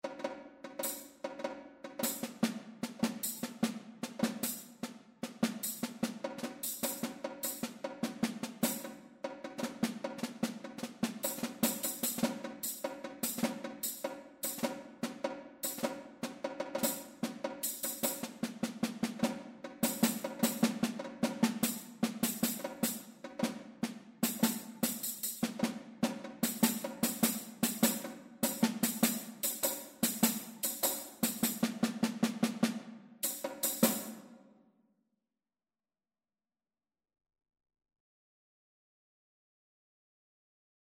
Snare Drum, Tenor Drum & Tambourine
1-snare-drum-tenor-drum-tambourine-audio.mp3